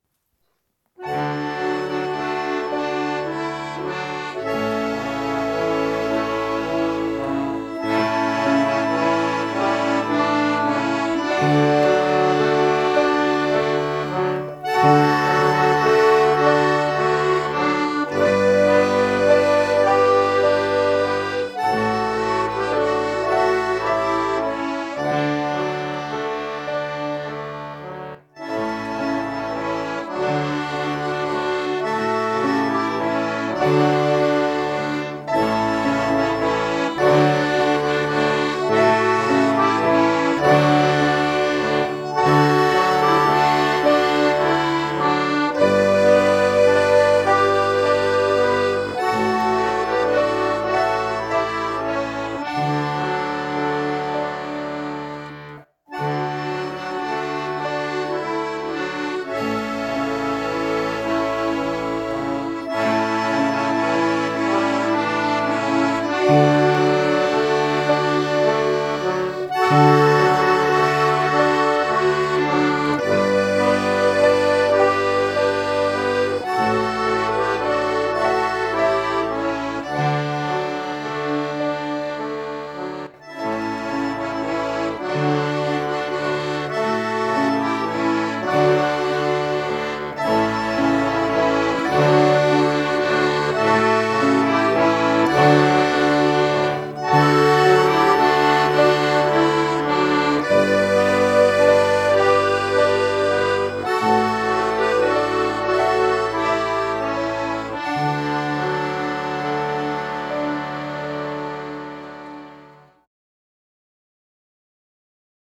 Deutsches Weihnachtslied 1860